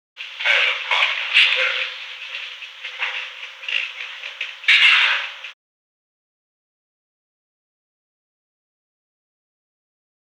Conversation: 394-017
Recording Device: Old Executive Office Building
The Old Executive Office Building taping system captured this recording, which is known as Conversation 394-017 of the White House Tapes.
The President met with an unknown man.